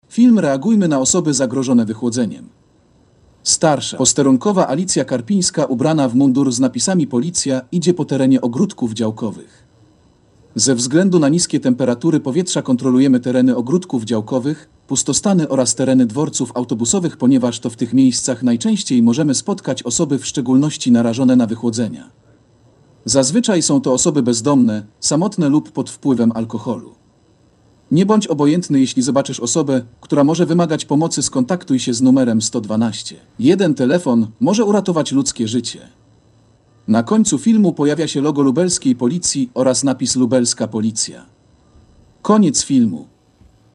Nagranie audio Audiodeskrypcja filmu reagujmy na osoby zagrożone wychłodzeniem